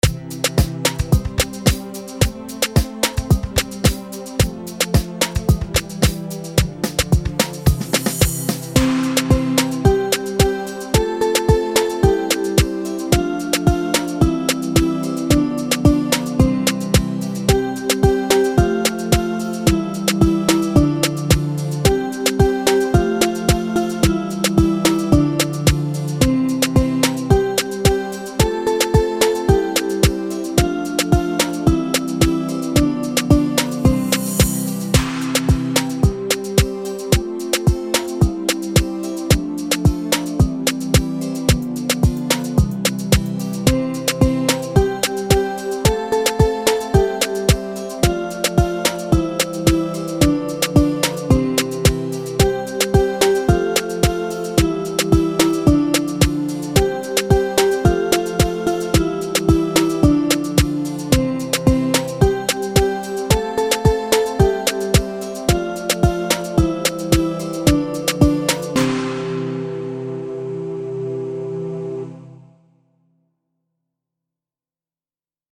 kids music
Nursery Rhymes & Kids Song